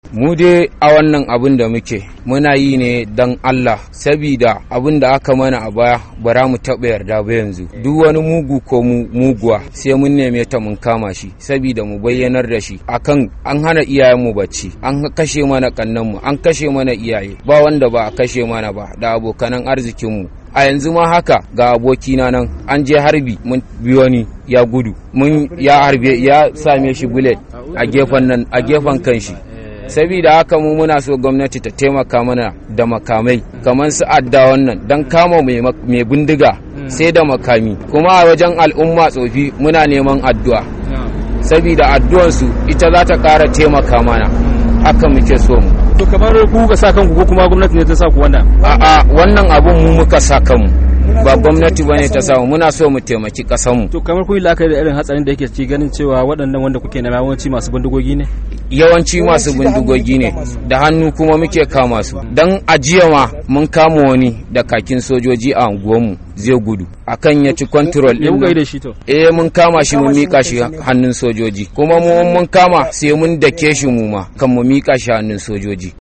daya daga cikin matasa biyun da aka yi hira da su kan dalilansu na fara farautar 'yan Boko Haram a cikin garin Maiduguri